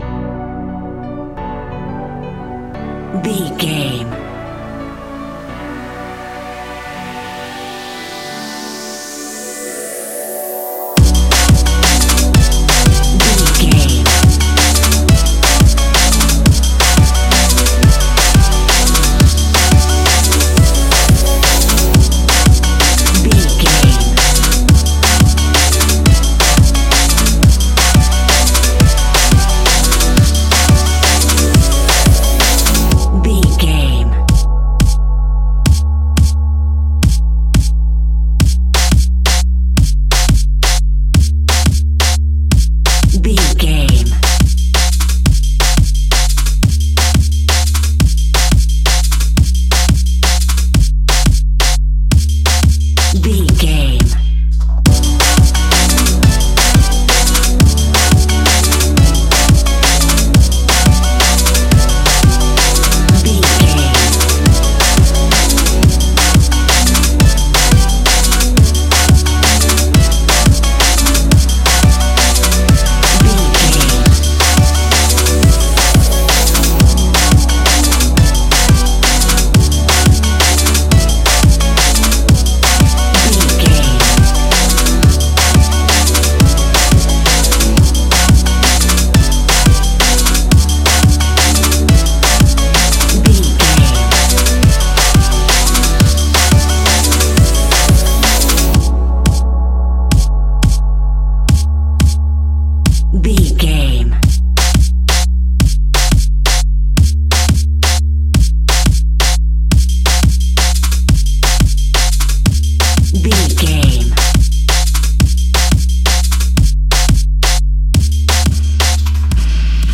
Ionian/Major
electronic
techno
trance
synthesizer
synthwave